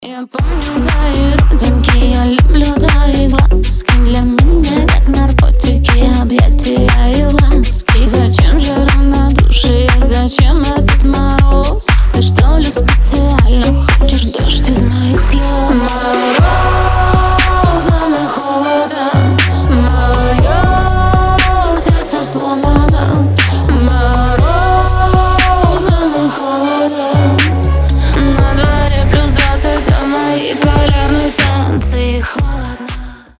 • Качество: 320, Stereo
ритмичные
женский голос